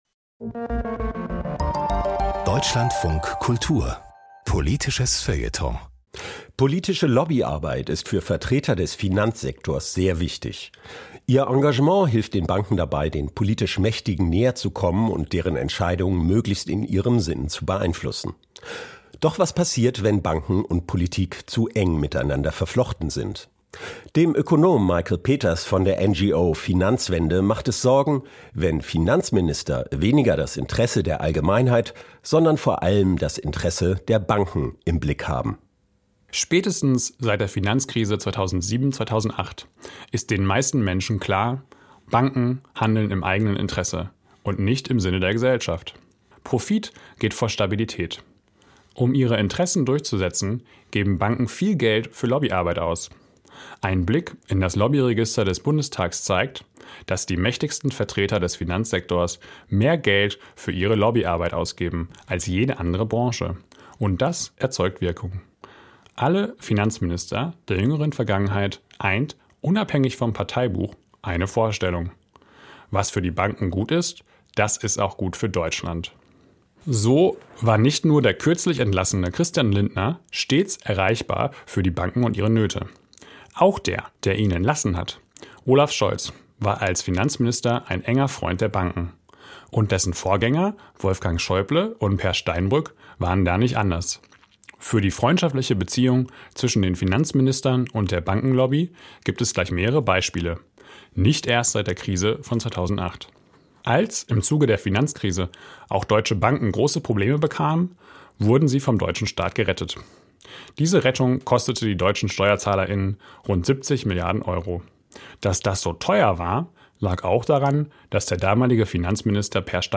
Kommentar: Der Finanzminister – Freund und Helfer der Banken